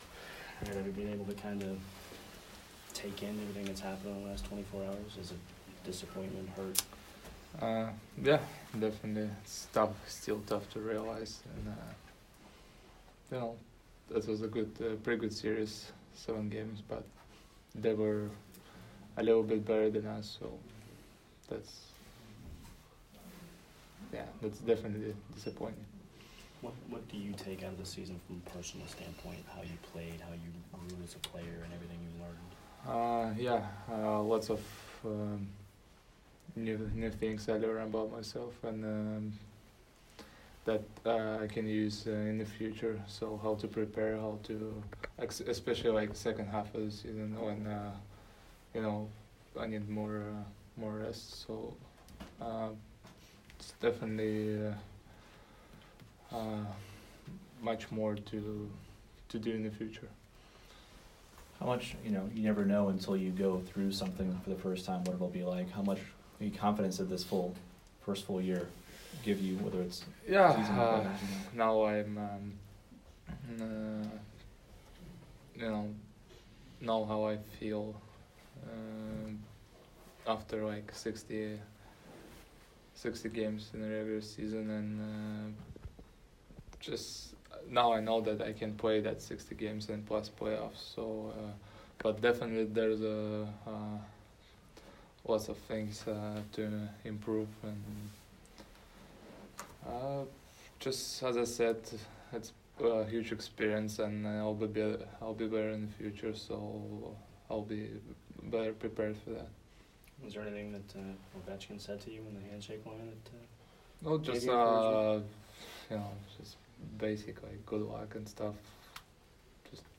Andrei Vasilevskiy Exit Interview 5/24